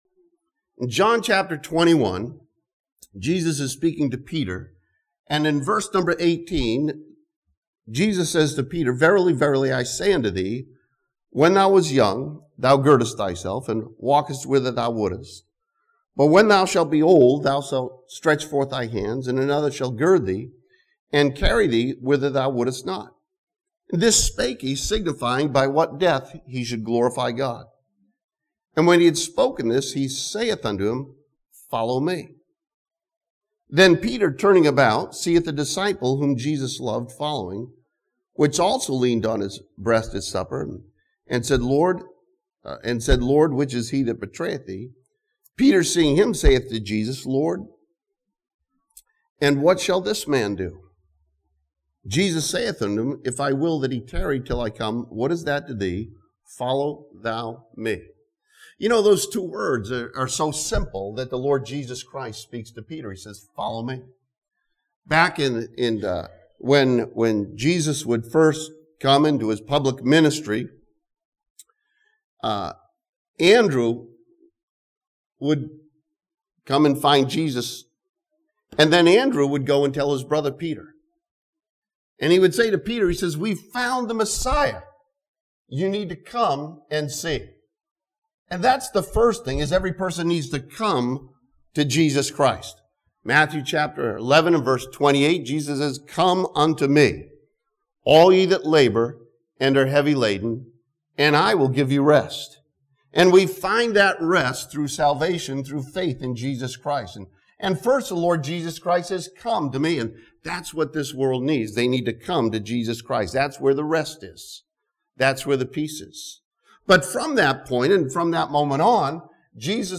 This sermon from Leviticus chapter 9 examines the burnt offering and learns the truth about when nothing is everything.